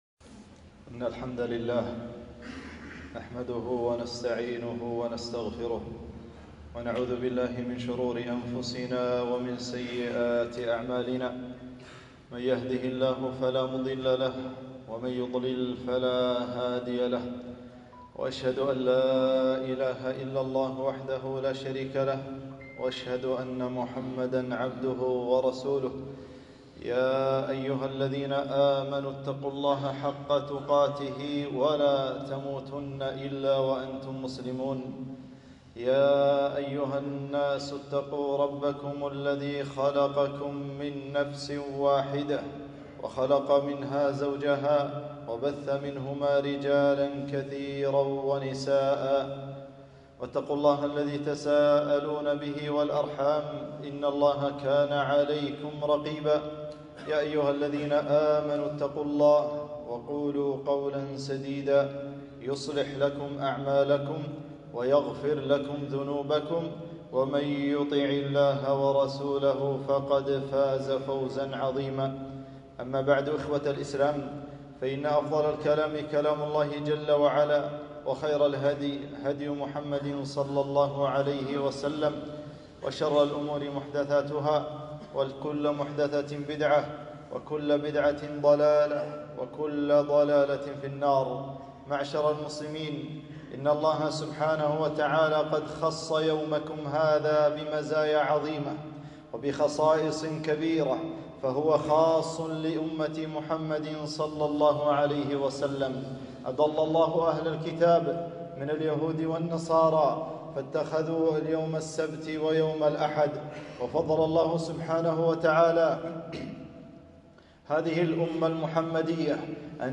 خطبة - فضل يوم الجمعة